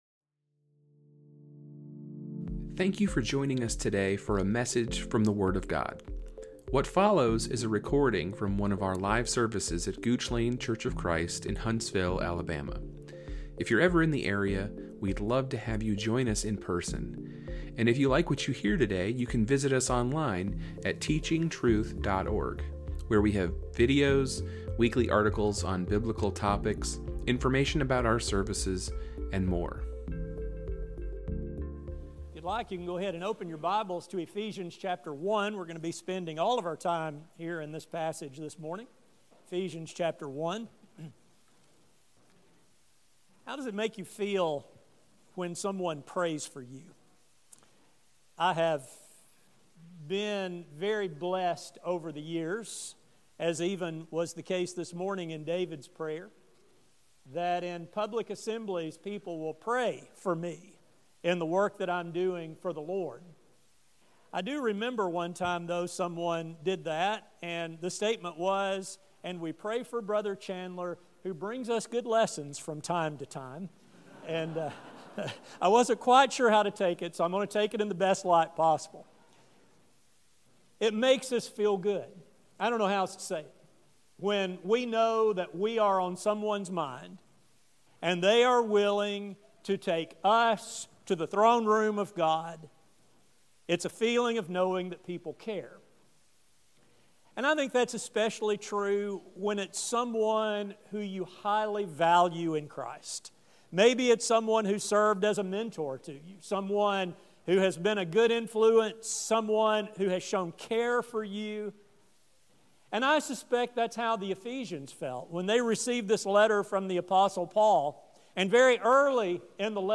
This sermon will explore the three requests he made for believers both then and to come.